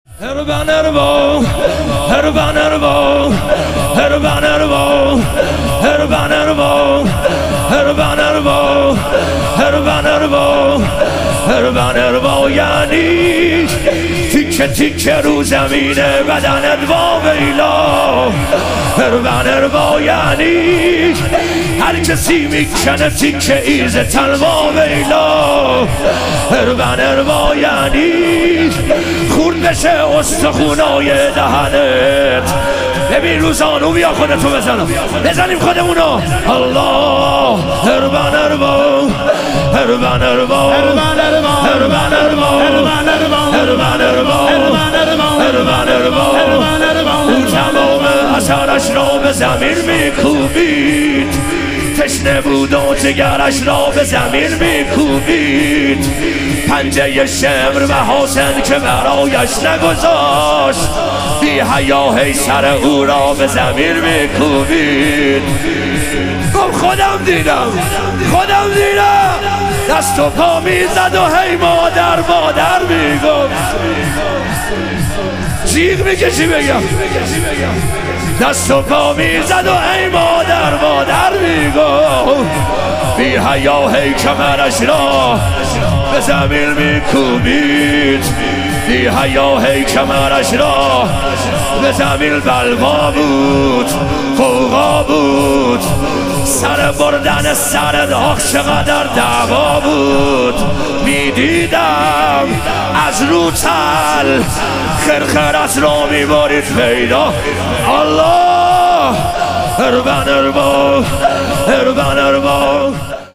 لطمه زنی
شب شهادت حضرت زینب کبری علیها سلام